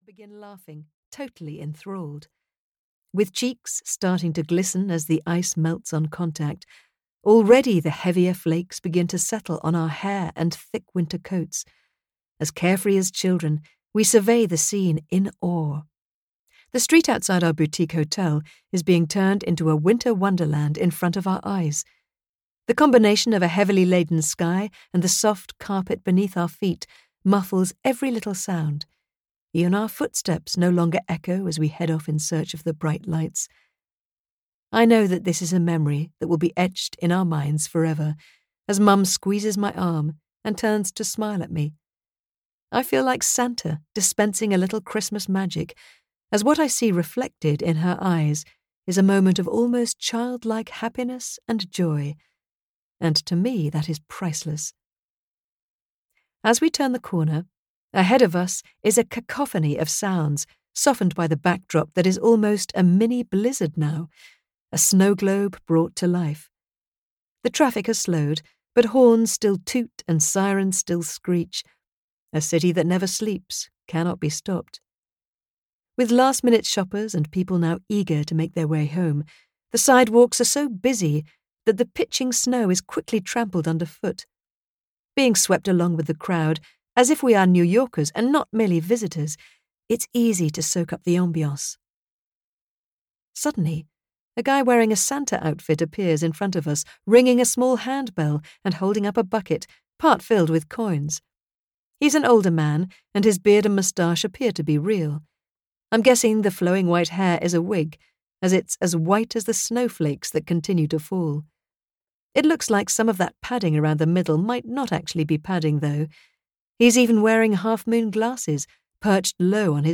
Snowflakes Over Holly Cove (EN) audiokniha
Ukázka z knihy